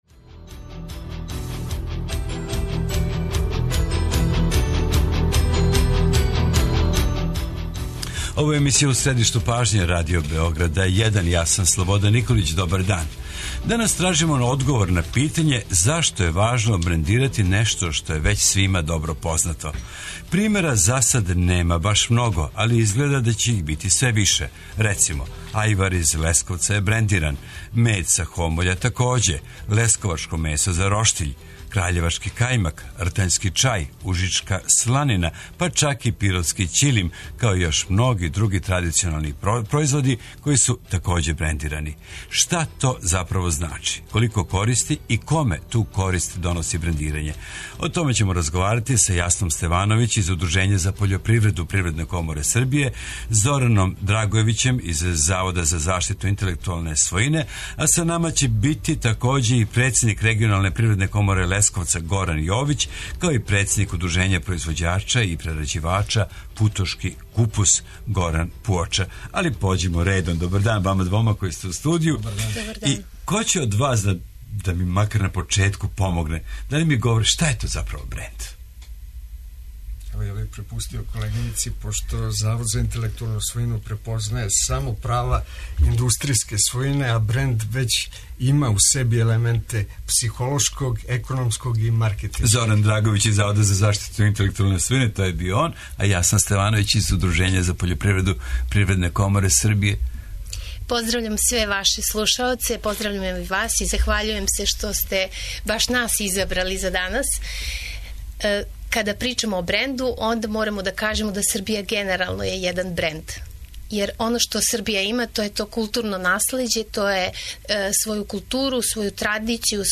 Koliko koristi donosi brendiranje? Odgovore na ova pitanja tražimo sa stručnjacima, predstavnicima regionalnih privrednih komora, Zavoda za intelektualnu svojinu kao i sa sa proizvođačima koji su odlučili da svom proizvodu daju zaštićeno ime ili - brend.